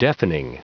Prononciation du mot deafening en anglais (fichier audio)
Prononciation du mot : deafening